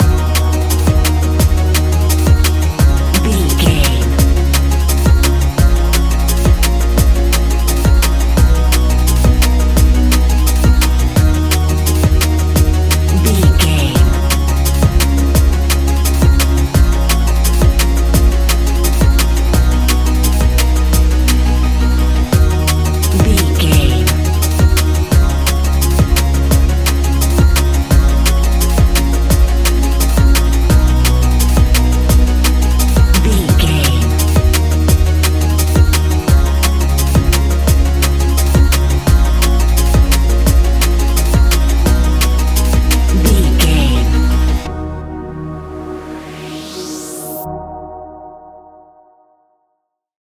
Ionian/Major
D♯
electronic
techno
trance
synths
synthwave
instrumentals